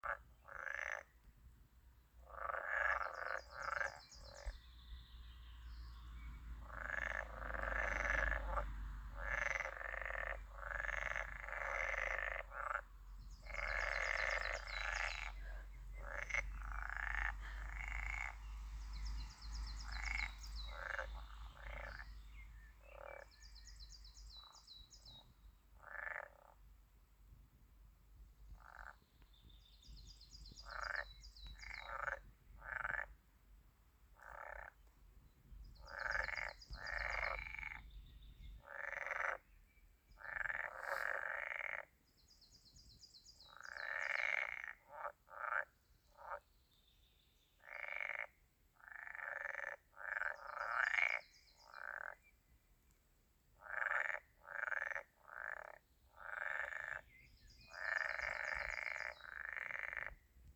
Damfrosken er også kjent for sin karakteristiske kvekking, særlig i parringstiden om våren.
Her kan du høre damfroskene kvekke i Paddetjern
damfrosk.m4a